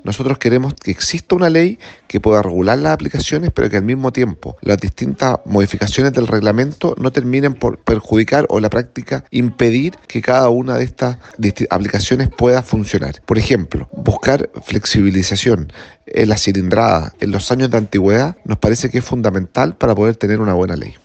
Al respecto, el diputado de la UDI e integrante de la Comisión de Obras Públicas, Transportes y Telecomunicaciones, Juan Antonio Coloma, aseguró que se debe garantizar un reglamento que no perjudique a las aplicaciones.